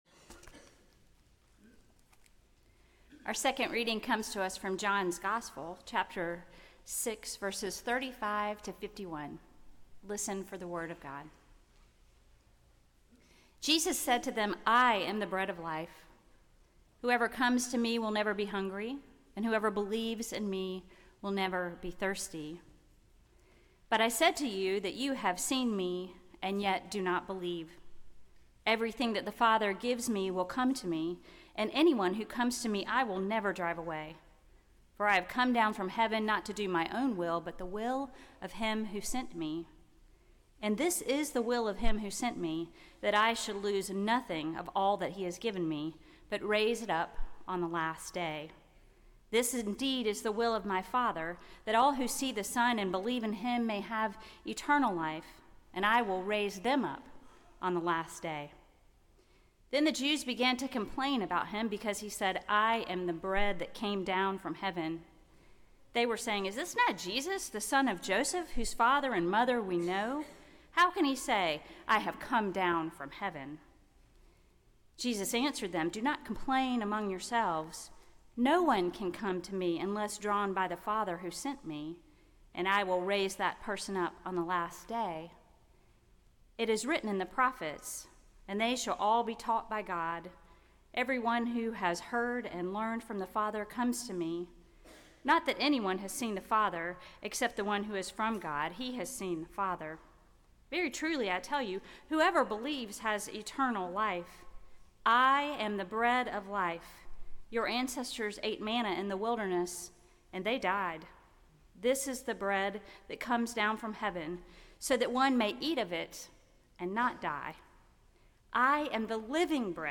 Sermon
World Communion Sunday